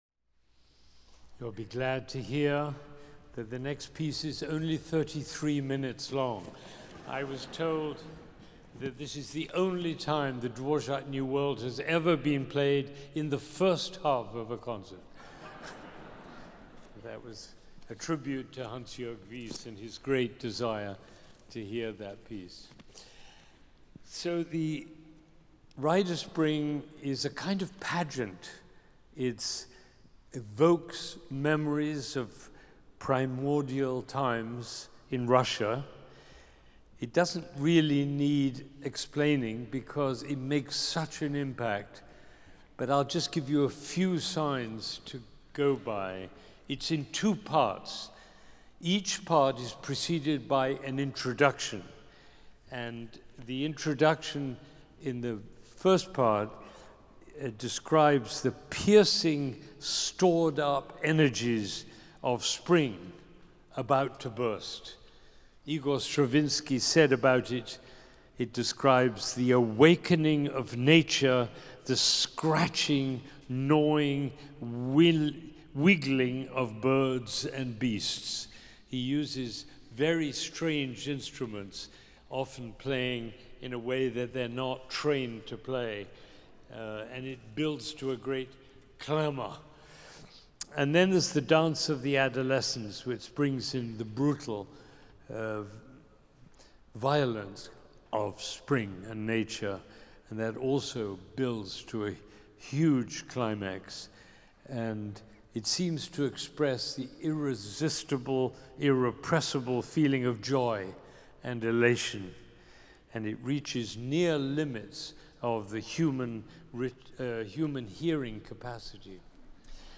Recorded Live at Symphony Hall on November 3rd, 2024. Boston Philharmonic Youth Orchestra Benjamin Zander, conductor